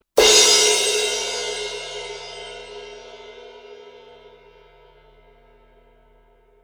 Explosive and penetrating, high-pitched volume is the strength of this cymbal of power. Crash Cymbals.
Loud Hit Performance